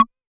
RDM_TapeA_SY1-Perc03.wav